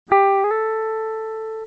Slide Audio Beispiel:
(Englisch, slide = rutschen) Mit der Greifhand werden eine oder mehrere Saiten angeschlagen, gleichzeitig verschiebt sich die Greifhand um einen oder mehrere Bünde.
slide.mp3